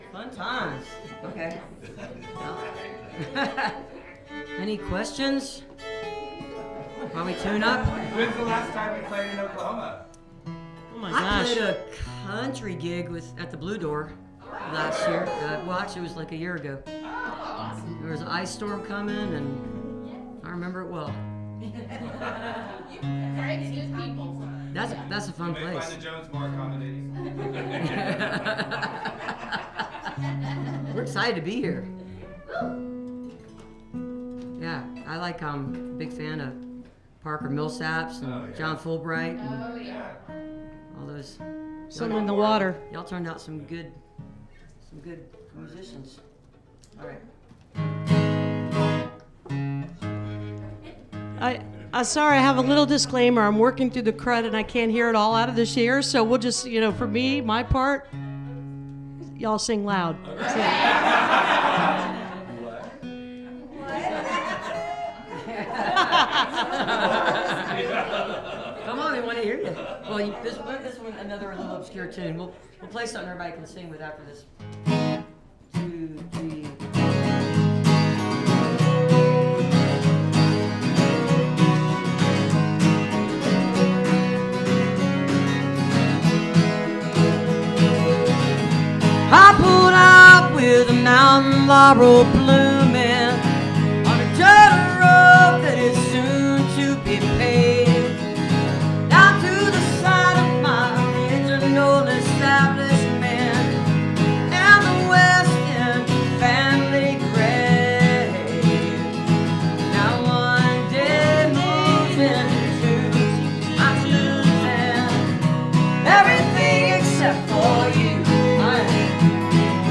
(captured from a youtube video)